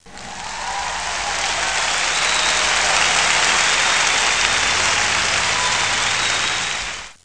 applaus1.mp3